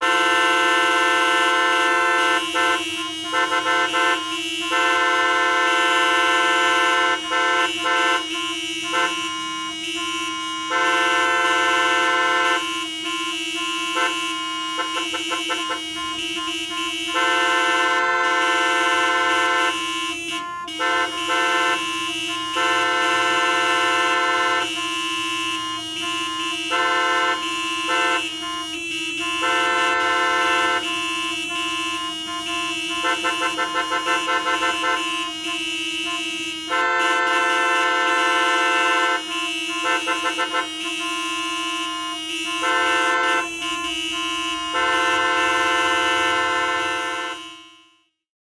Звуки множества гудков в пробке, все сигналят друг другу